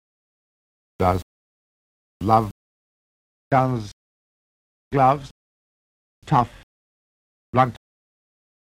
Listen to the darker [ɑ]-like STRUT by RP03 (Figure 3):